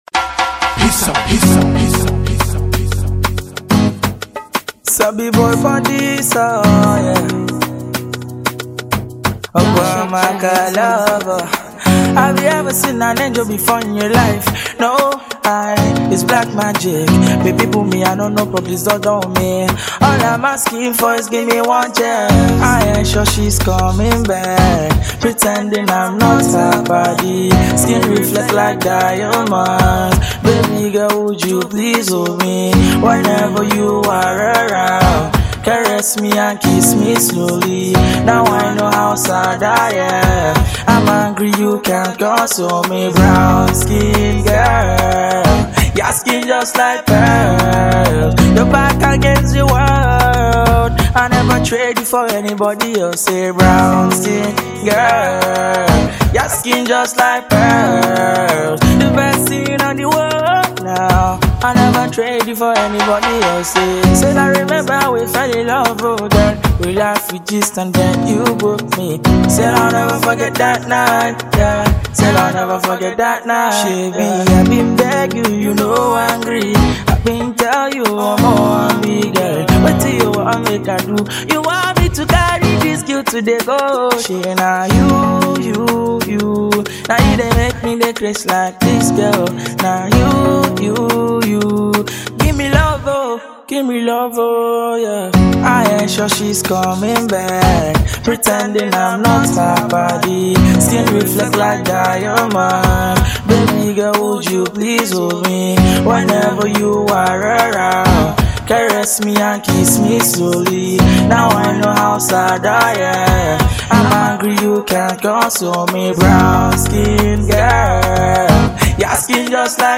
Young talented singer